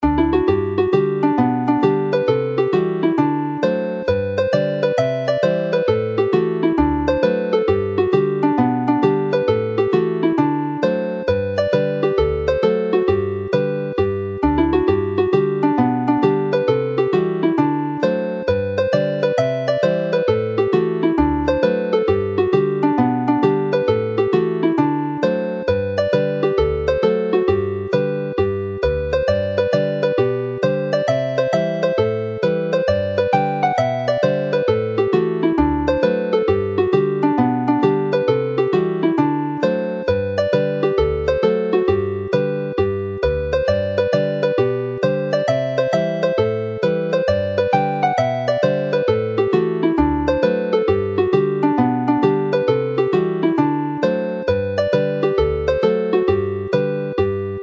Alawon Cymreig - Set Y Mwnci - Welsh folk tunes to play
The Gower Reel as a hornpipe
Chwarae'r alaw fel pibddawns